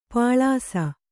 ♪ pāḷāsa